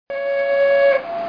260系ドアブザー
ドアが閉まる前にちょっと流れます。ごく普通の音。
260buzzer.mp3